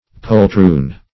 Poltroon \Pol*troon"\, a.